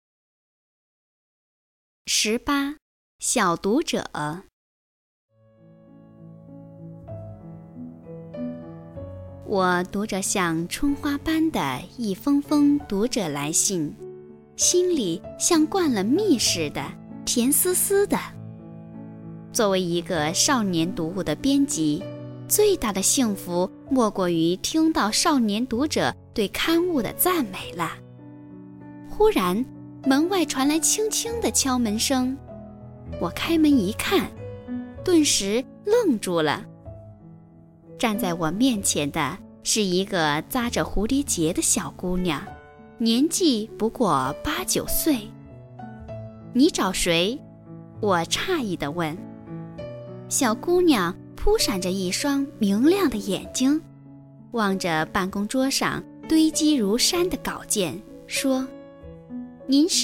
语文三年级上西师版18《小读者》课文朗读_21世纪教育网-二一教育